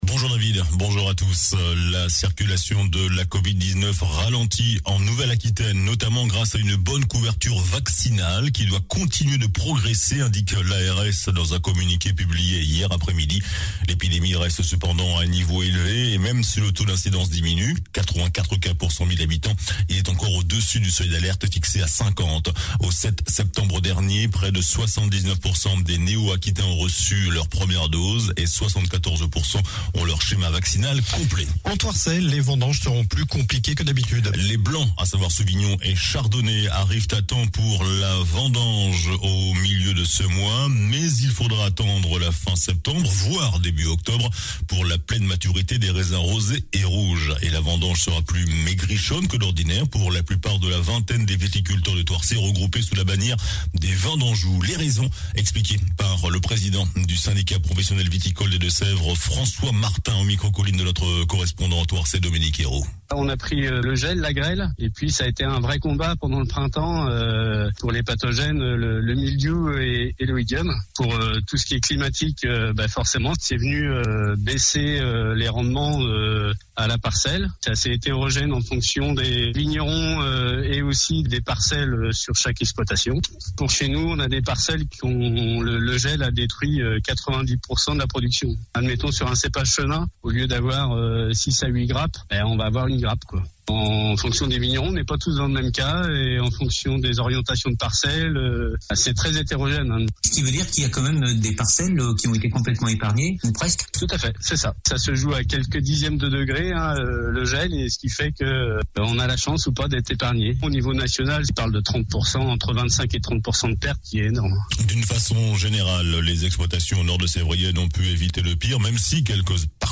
JOURNAL DU SAMEDI 11 SEPTEMBRE